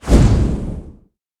Magic Spell_Simple Swoosh_6.wav